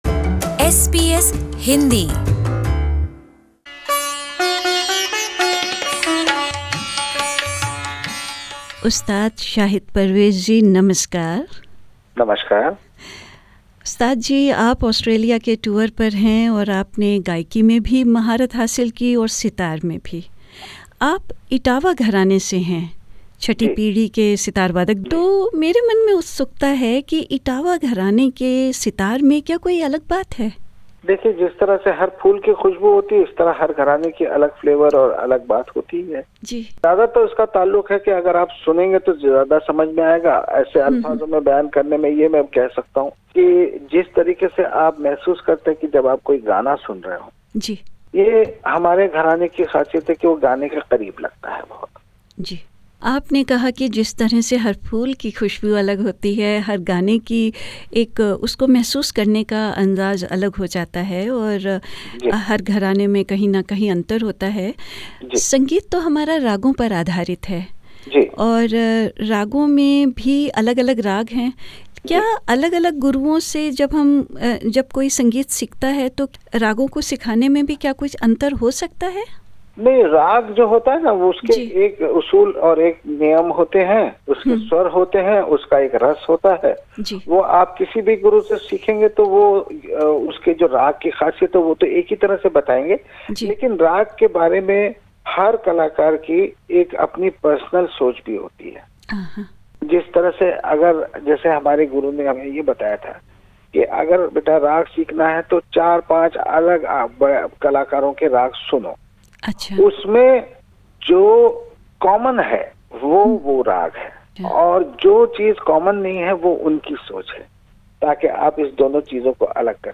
SBS Hindi met with Ustad Parvez where he spoke about his passion for music, his Etawah Gharana and also about his father – noted sitar player Ustad Aziz Khan, who was a professional music composer in Bollywood in the 50s.